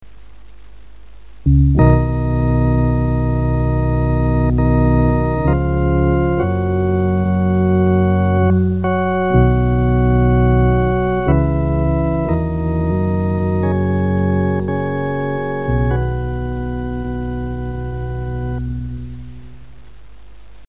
Masonic Music for Lodge and Chapter
Organ.